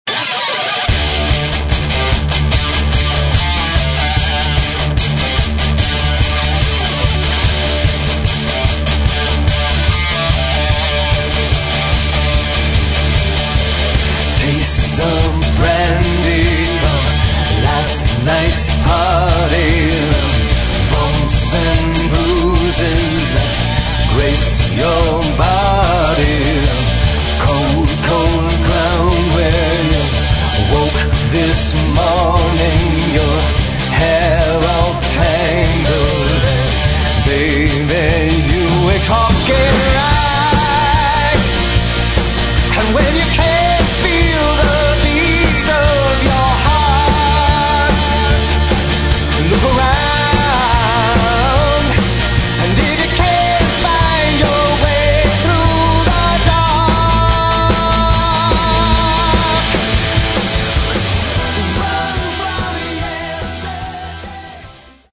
Рок
Альбом очень интересный, прекрасно записаный, отличный звук.